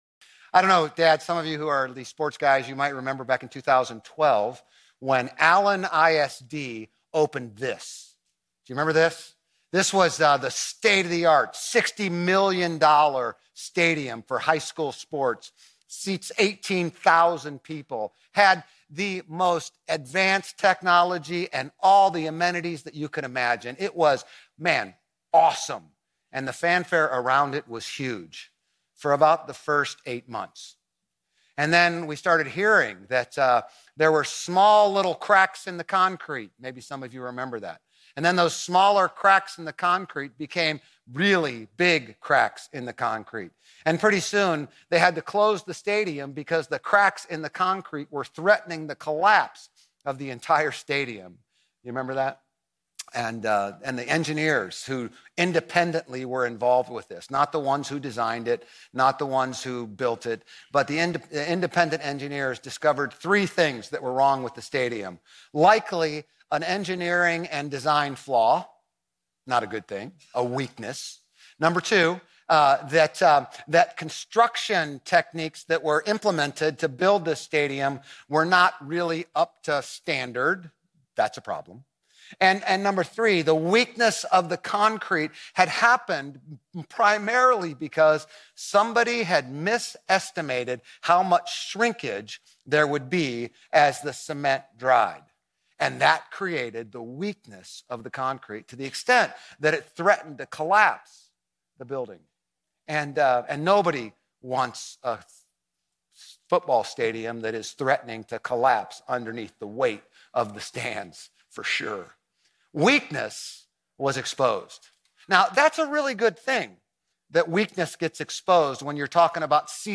Grace Community Church Old Jacksonville Campus Sermons 6_15 Old Jacksonville Campus Jun 16 2025 | 00:35:58 1x 00:00 / 00:35:58 Subscribe Share RSS Feed Share Link Embed